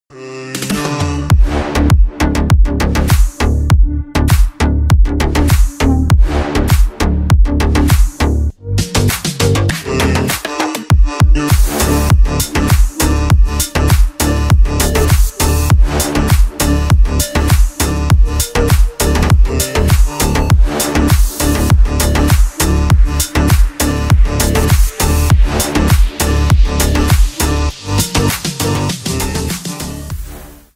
Танцевальные
громкие